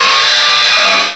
cry_not_whirlipede.aif